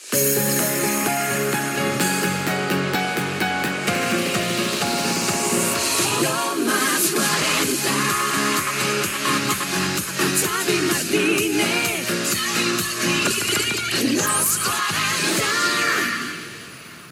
Indicatiu del programa
Musical
FM